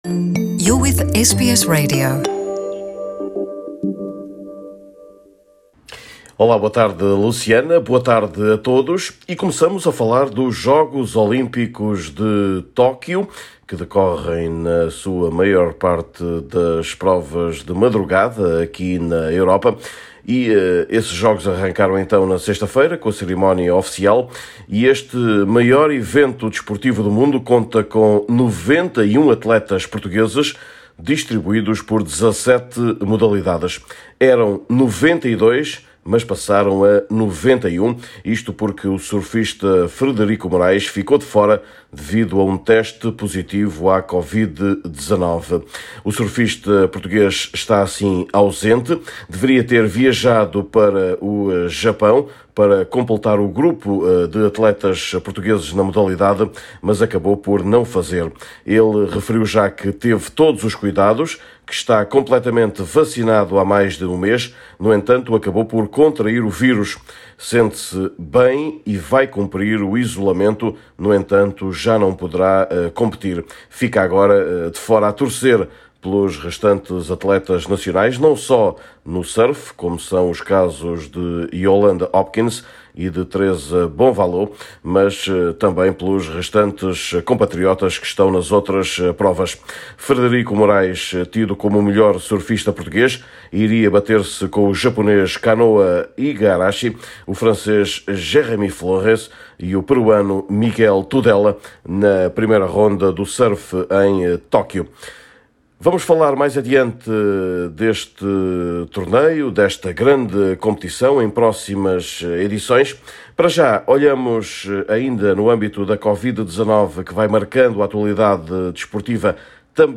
direto de Lisboa